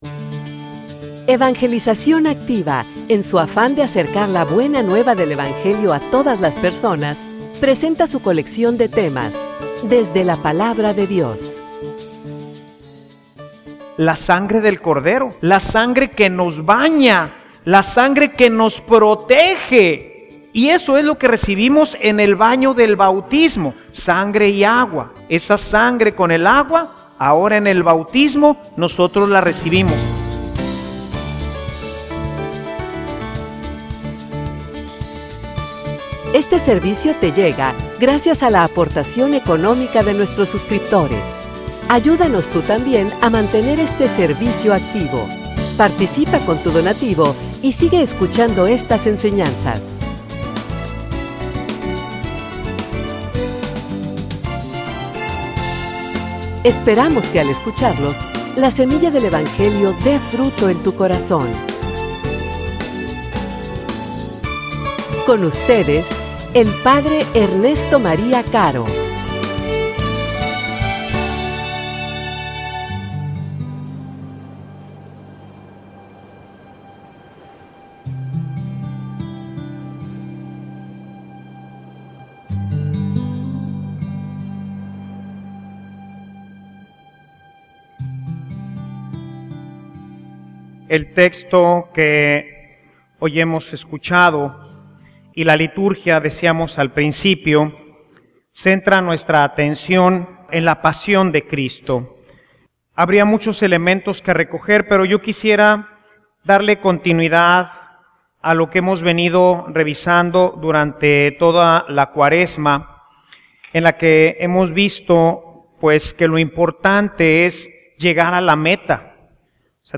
homilia_Su_sangre_garantia_de_proteccion.mp3